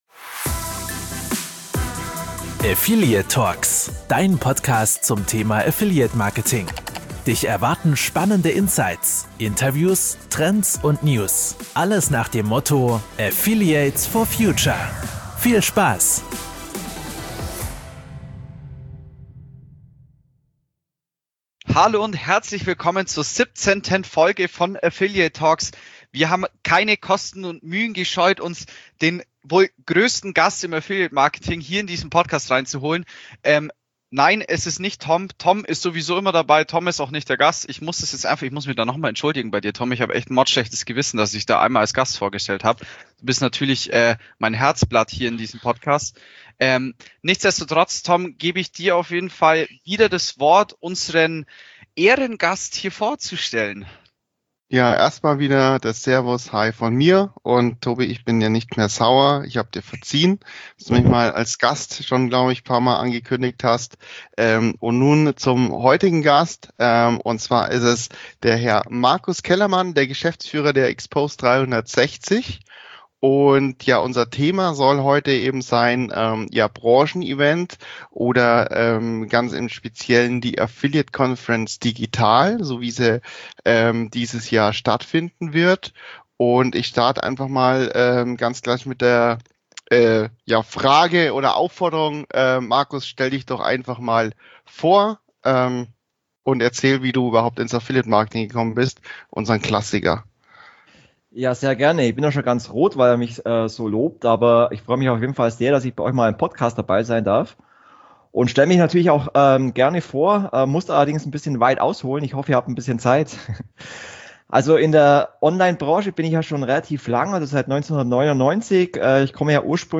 Affiliate TalkxX 17: Events im digitalen Zeitalter // Interview